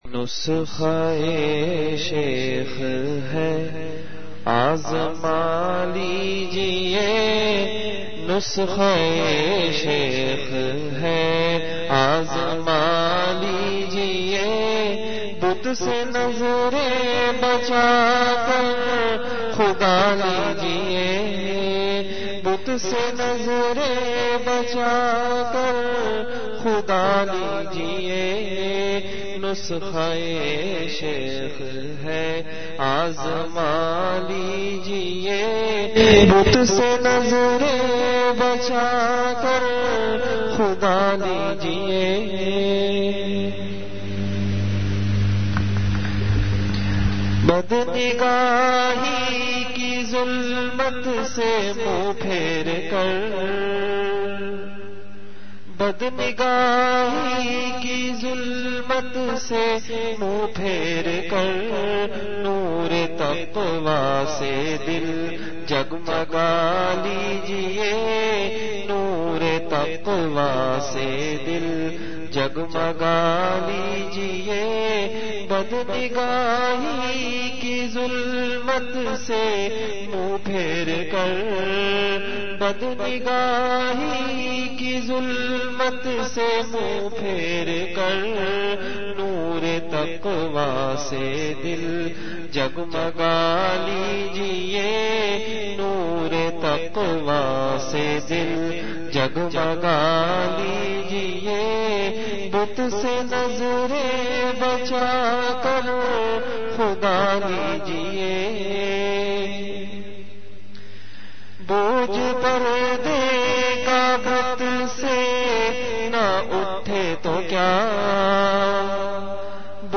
Delivered at Home.
Majlis-e-Zikr
Event / Time After Isha Prayer